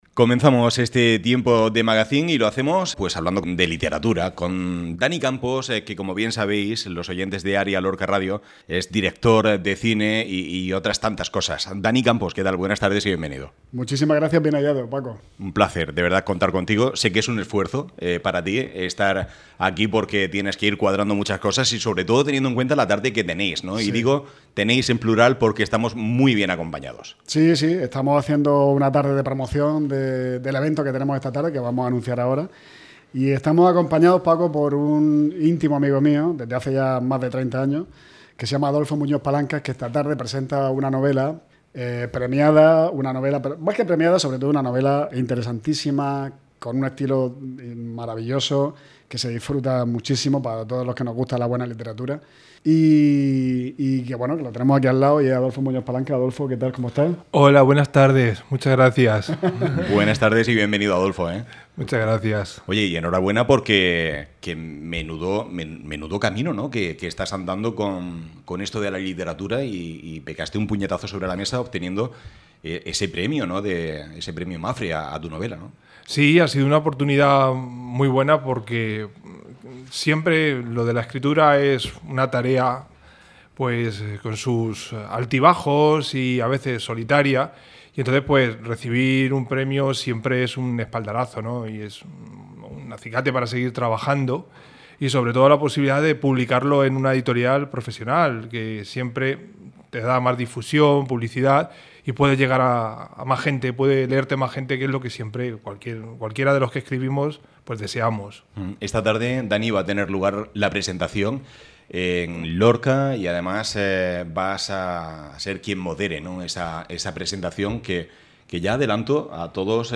Escucha aquí la entrevista previa a la presentación de la novela